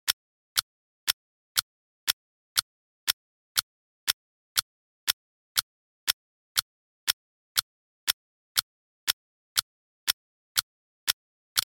دانلود صدای ساعت 17 از ساعد نیوز با لینک مستقیم و کیفیت بالا
جلوه های صوتی